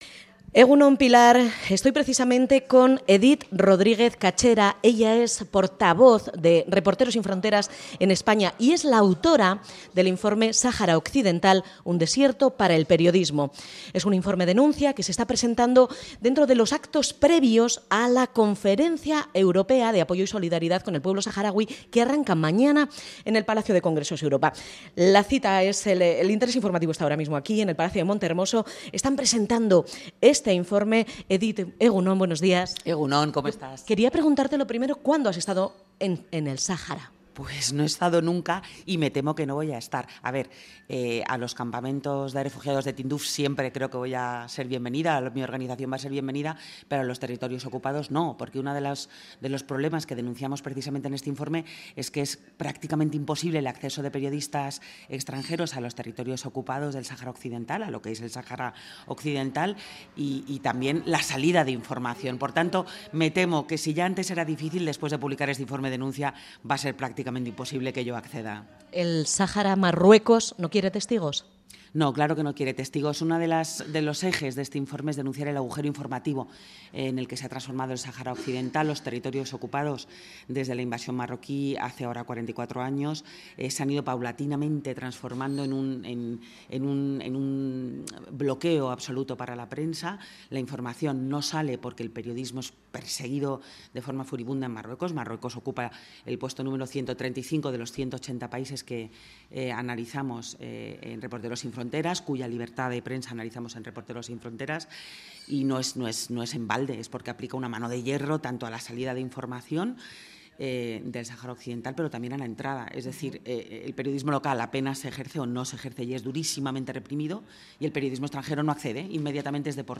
presenta en Gasteiz el 1er informe sobre la libertad de prensa en el Sáhara Occidental